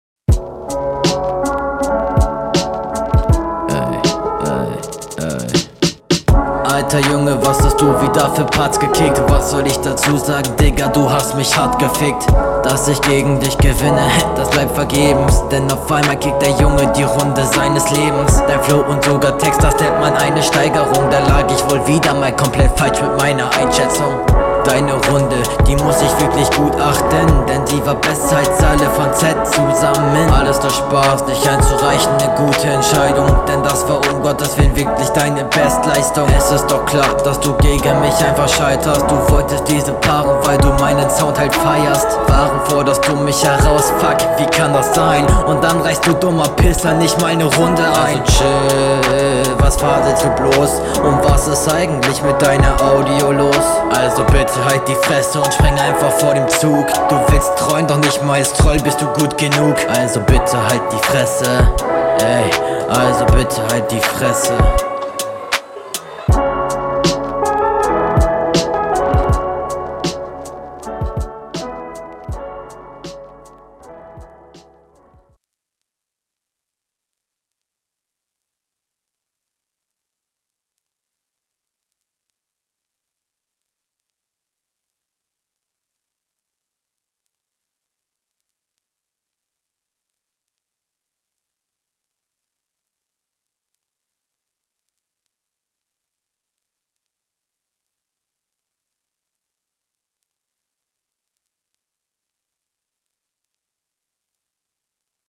joa ist solide geflowt kingt ganz ok und inhaltlich echt funny und cool dafür dass …